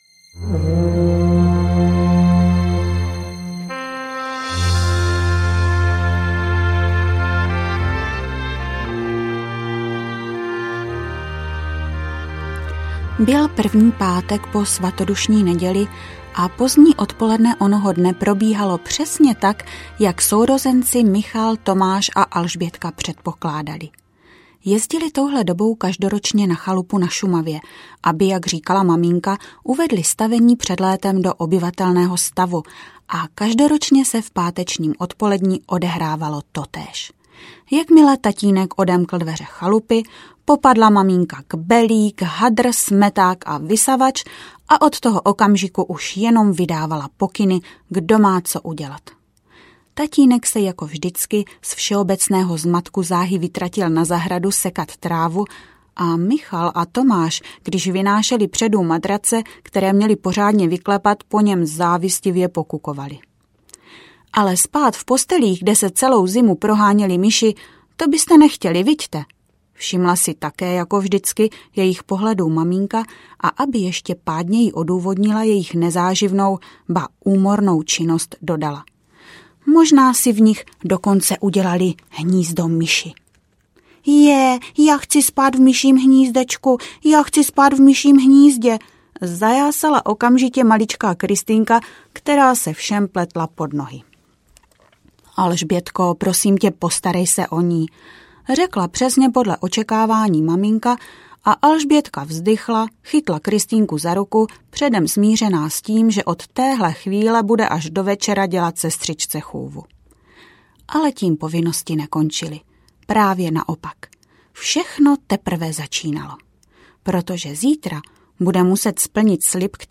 Popoupo aneb Podivuhodná pouť pouští audiokniha
Ukázka z knihy
popoupo-aneb-podivuhodna-pout-pousti-audiokniha-0